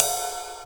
• HQ Cymbal Sound Clip F Key 05.wav
Royality free cymbal tuned to the F note. Loudest frequency: 6288Hz
hq-cymbal-sound-clip-f-key-05-XLH.wav